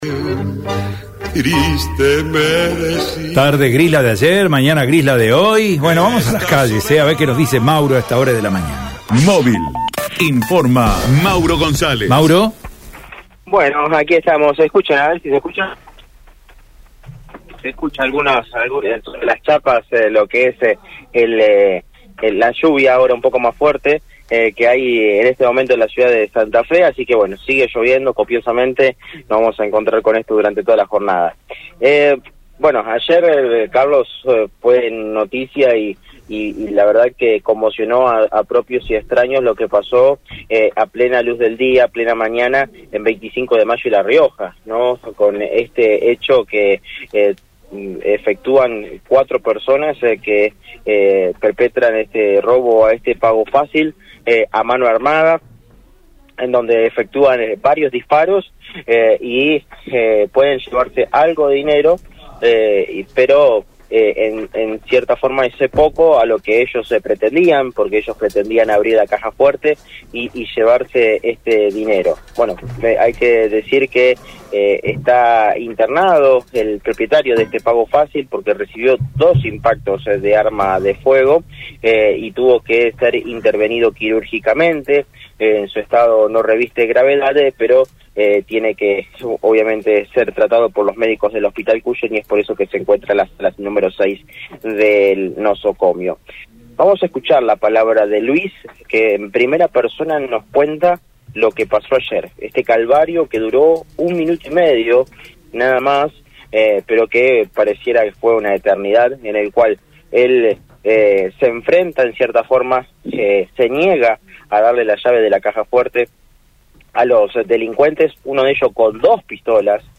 AUDIO DESTACADOPoliciales